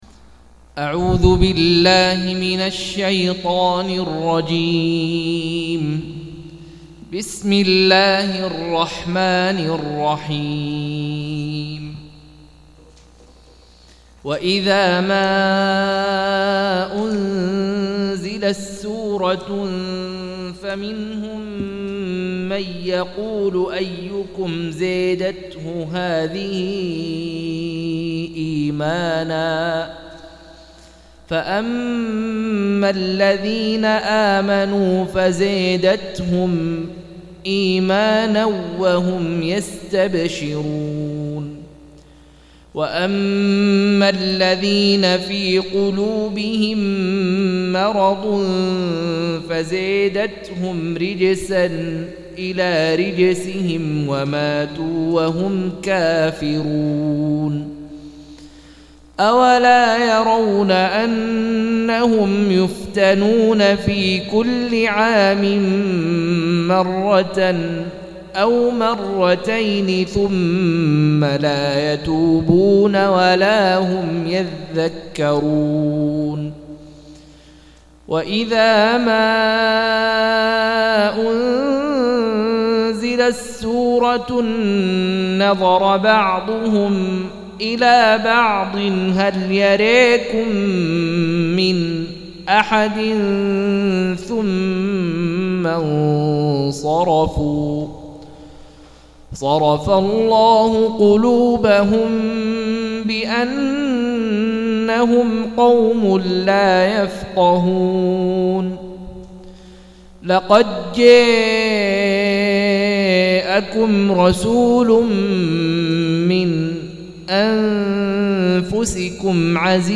197- عمدة التفسير عن الحافظ ابن كثير رحمه الله للعلامة أحمد شاكر رحمه الله – قراءة وتعليق –